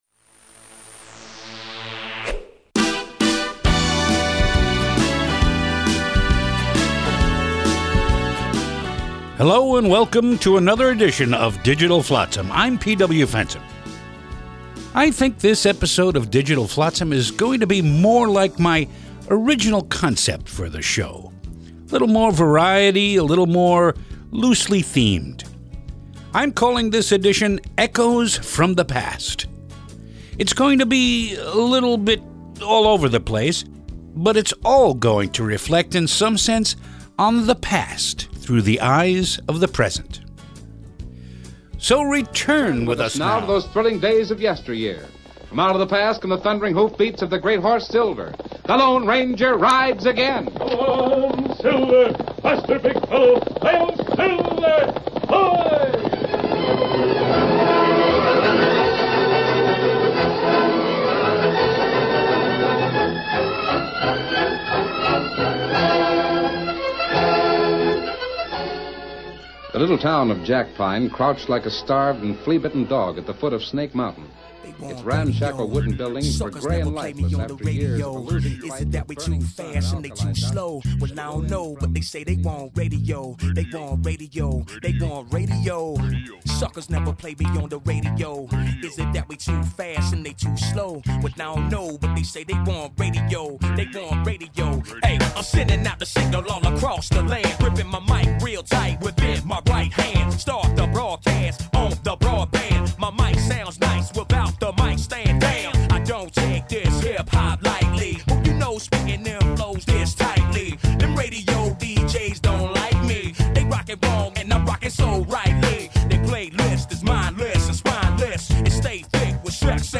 It appeared for several years nationally on Sirius Satellite Radio. We are proud to offer these great spoken word pieces again.